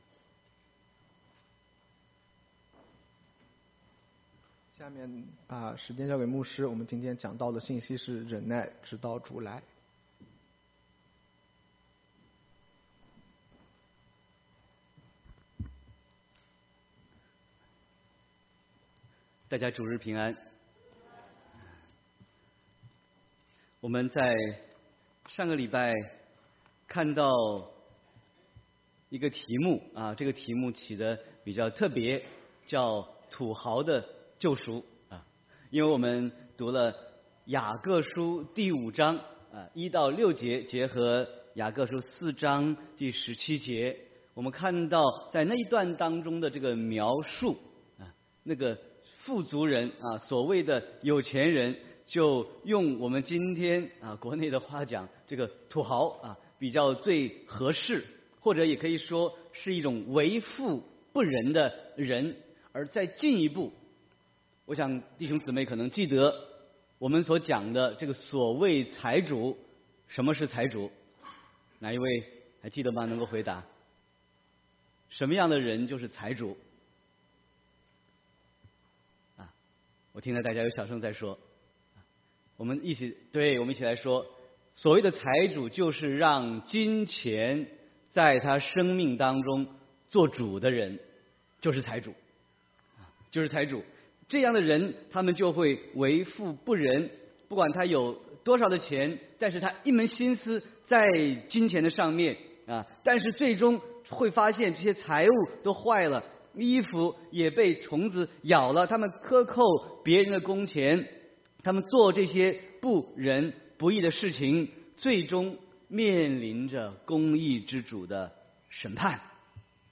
Sermon 7/1/2018